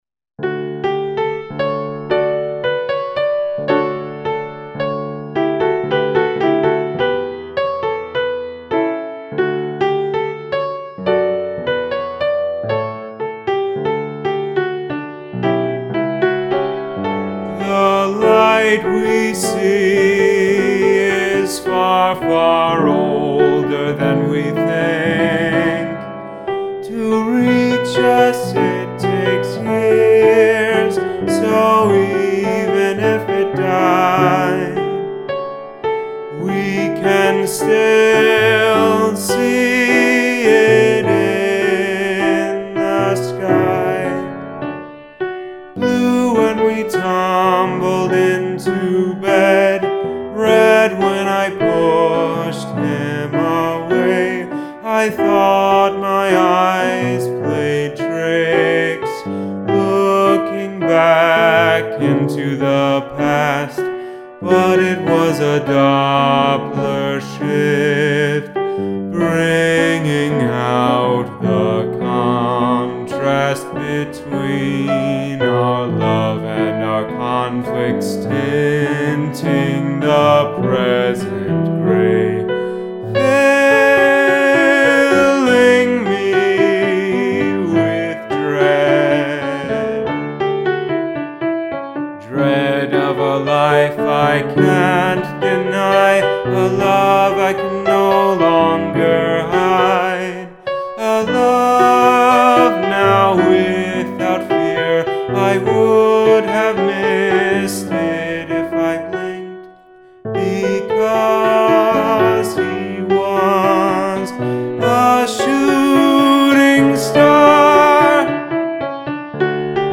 cast recording